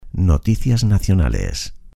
Locución gratuita para programas de televisión. Noticias nacionales.
noticias_nacionales_locucion_television_locutortv.mp3